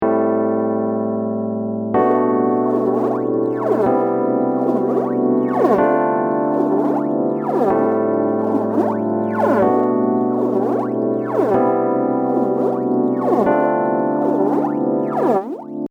LFOのスピードを調整するとモジュレーションスピードが変えられて怪しさ満点のコードトラックを作ることができたりします。例えば、細かくすることこんな感じ。